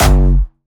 Jumpstyle Kick 4
2 F#1.wav